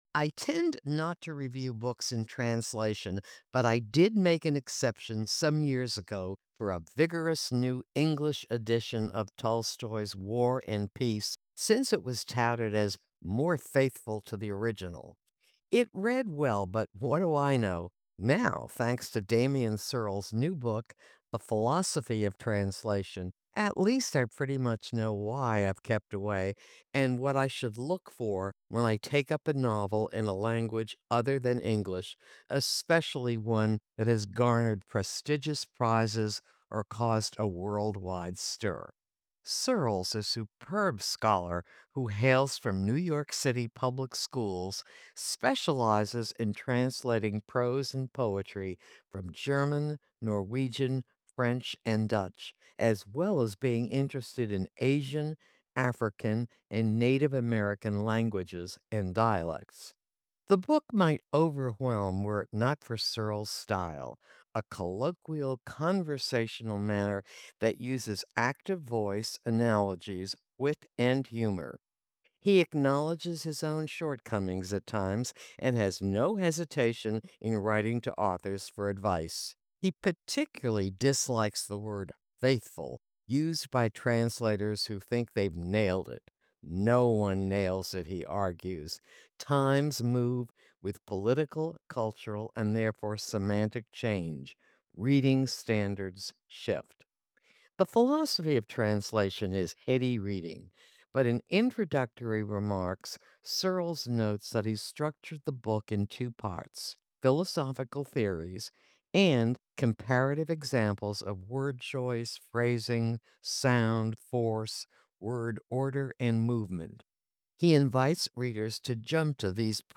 Book Review: The Philosophy of Translation 4:45